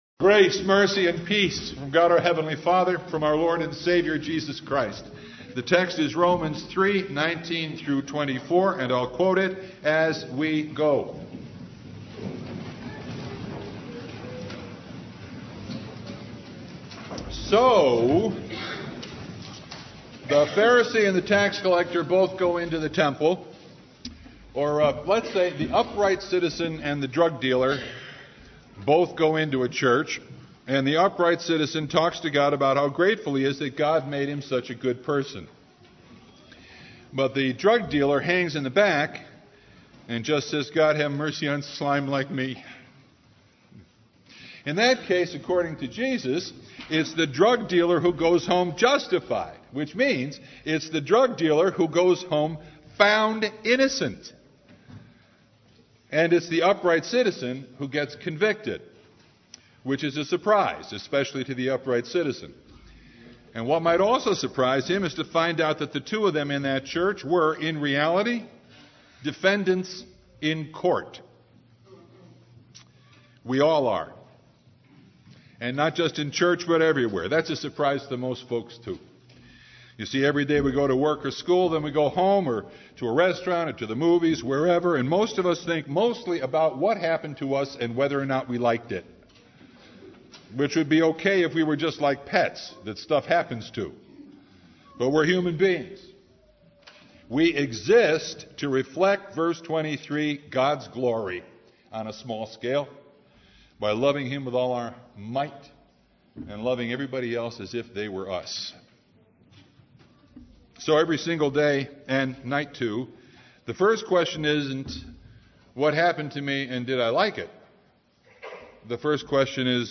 Passage: Romans 3:19-24 Service Type: Reformation
Sermon Only